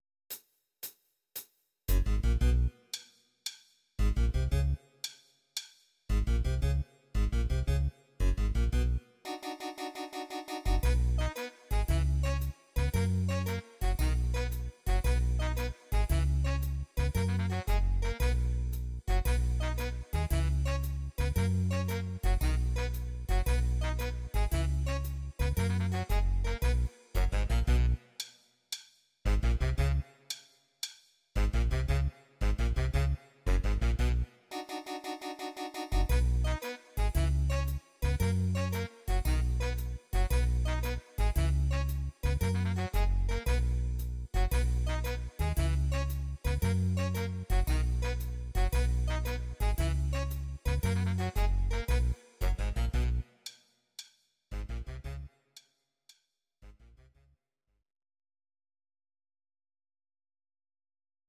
Audio Recordings based on Midi-files
Musical/Film/TV, Instrumental, 1960s